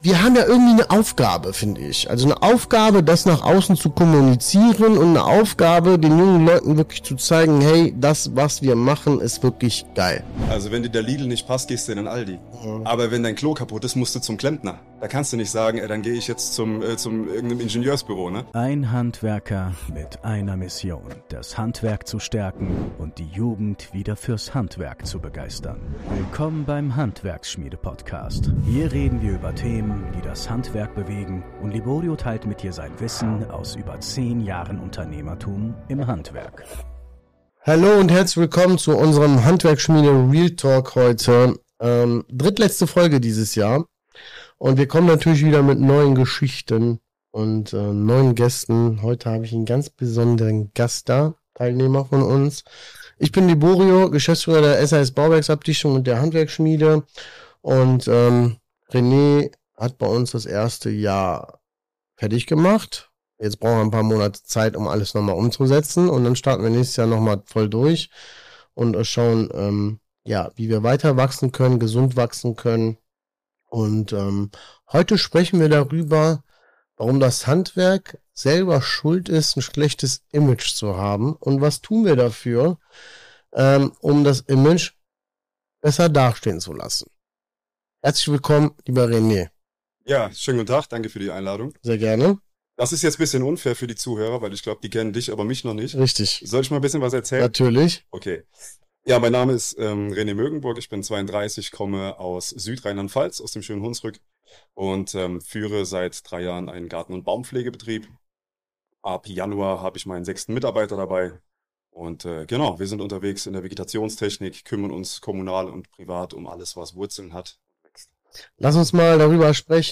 Was das wahre Problem mit dem Handwerk ist | Interview